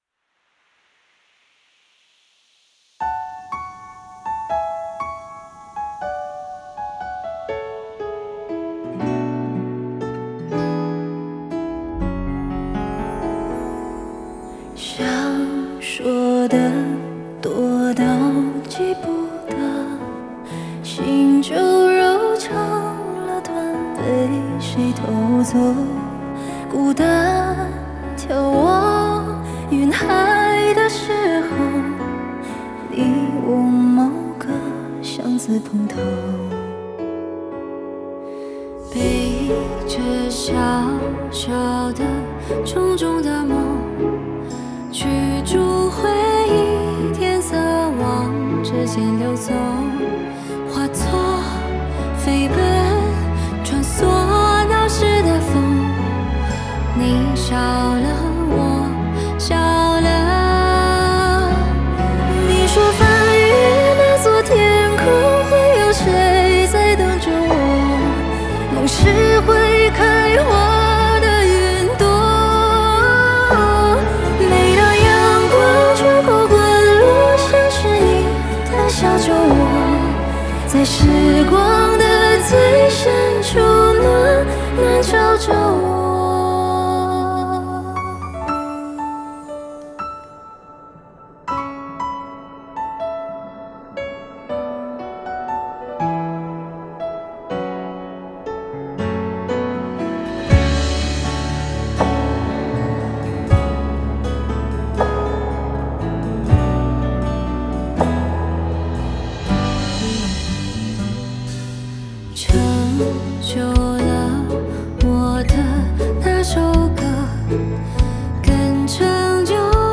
鼓
打击乐
贝斯
吉他
钢琴
键盘
合音